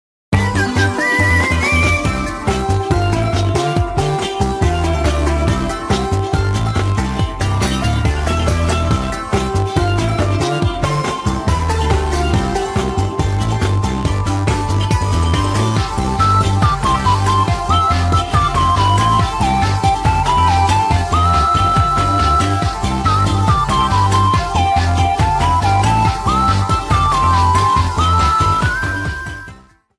Fair use music sample